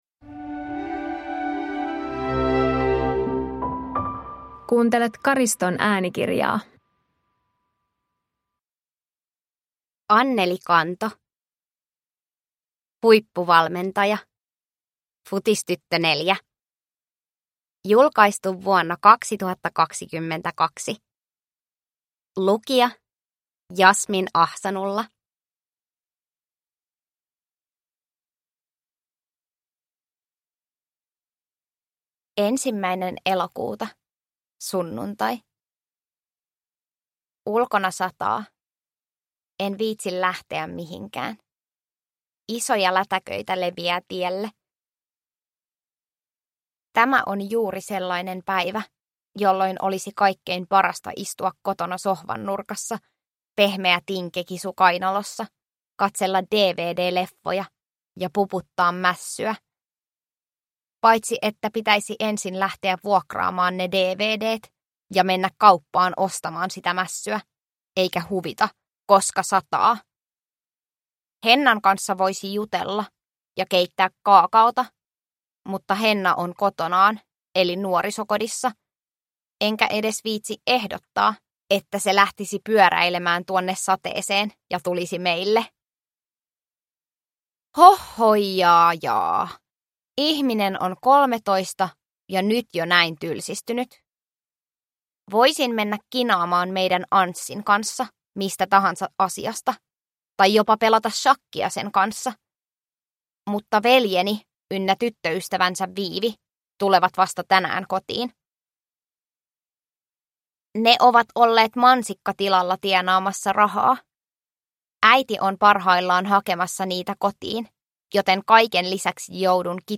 Huippuvalmentaja – Ljudbok – Laddas ner
Uppläsare: